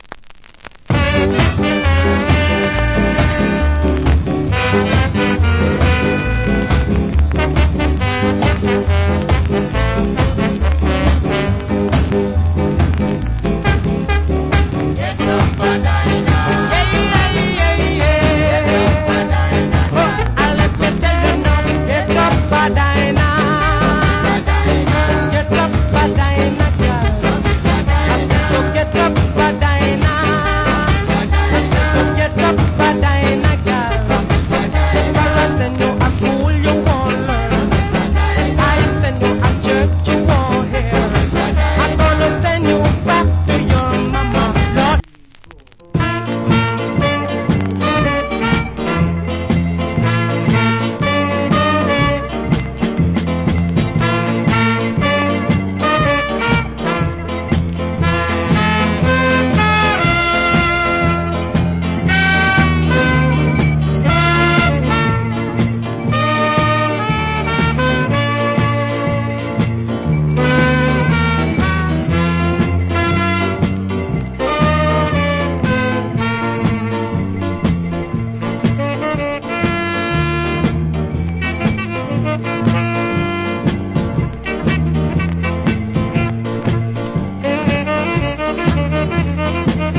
Wicked Ska Vocal.